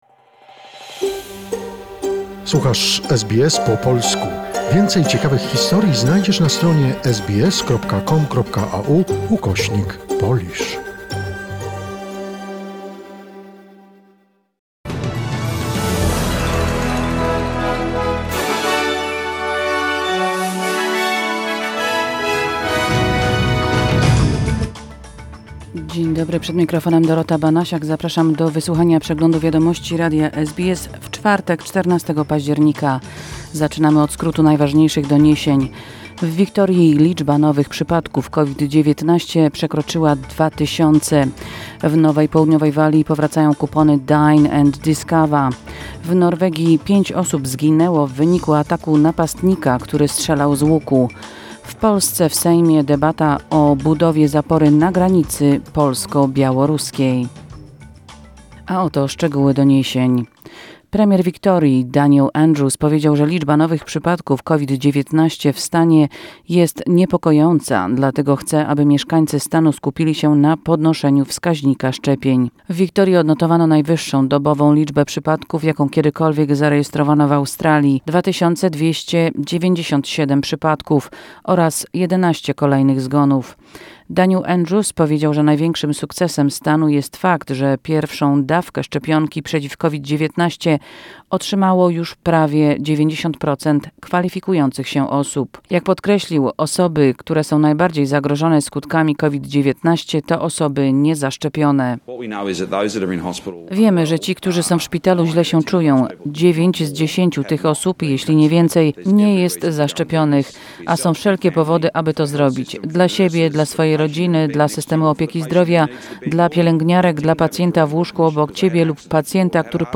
SBS News Flash in Polish, 14 October 2021